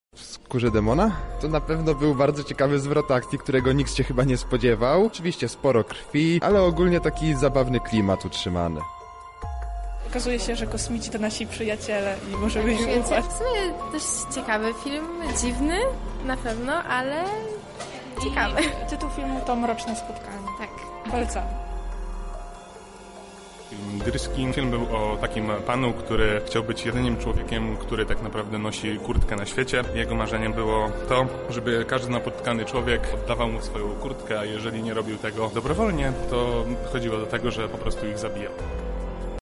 Zapytaliśmy uczestników, które filmy najbardziej przypadły im do gustu.
splat relacja